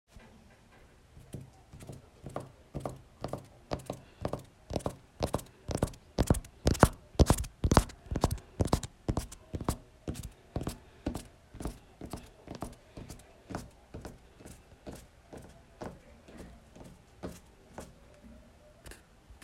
Horse Footsteps Bouton sonore
The Horse Footsteps sound button is a popular audio clip perfect for your soundboard, content creation, and entertainment.